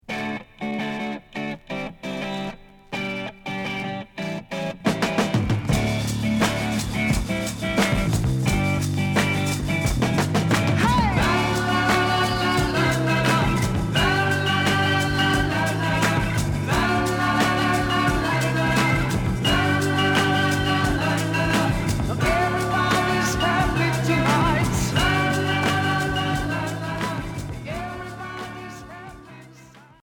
Pop Premier 45t retour à l'accueil